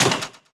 SE_CHIKEN_CAR_LAND.wav